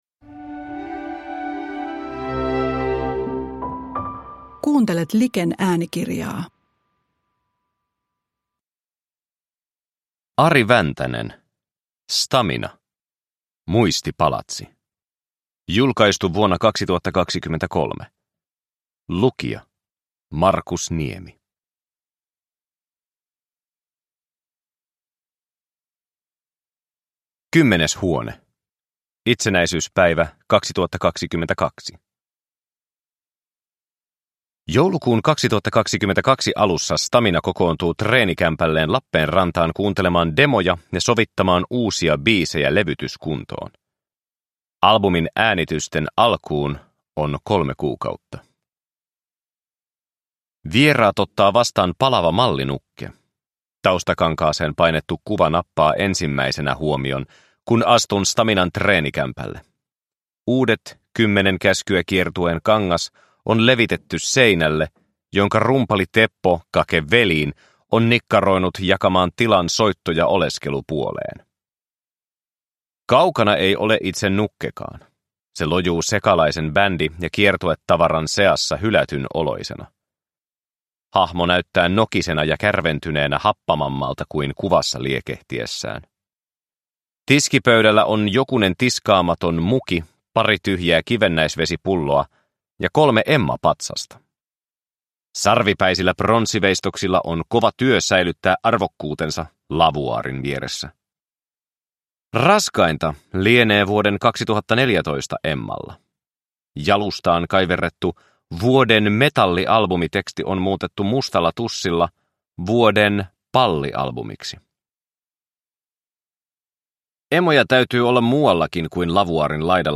Stam1na - Muistipalatsi – Ljudbok – Laddas ner